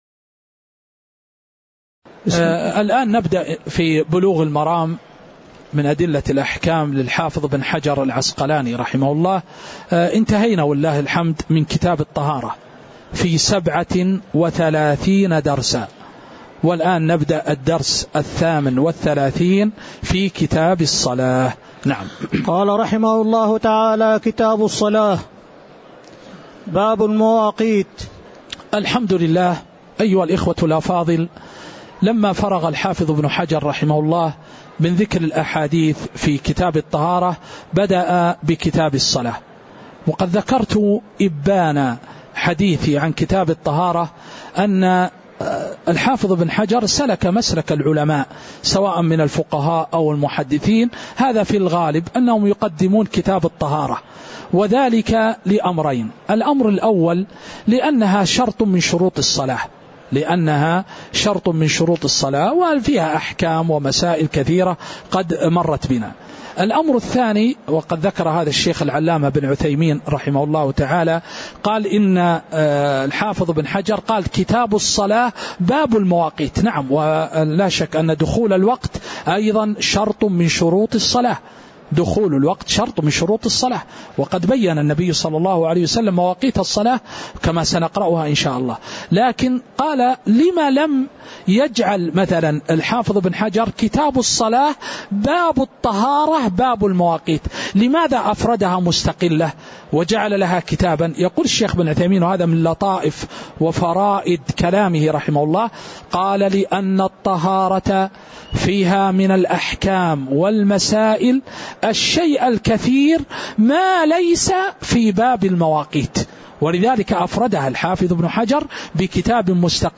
تاريخ النشر ٢٣ محرم ١٤٤٥ هـ المكان: المسجد النبوي الشيخ